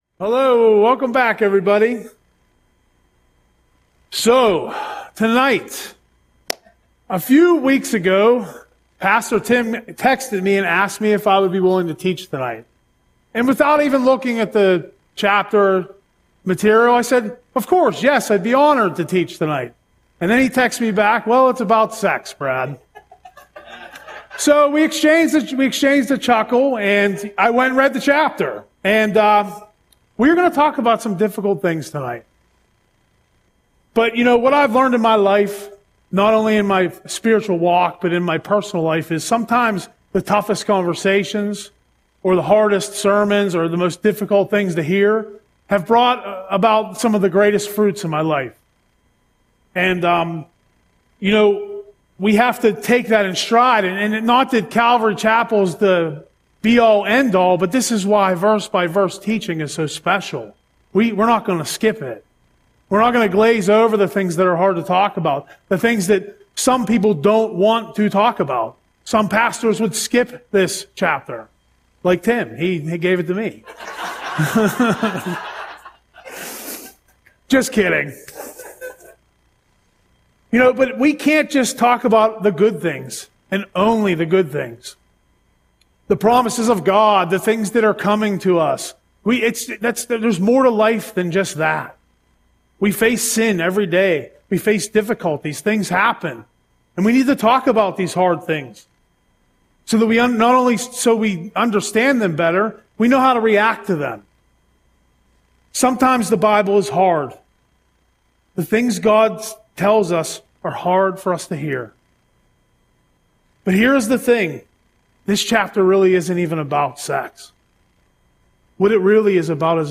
Audio Sermon - December 3, 2025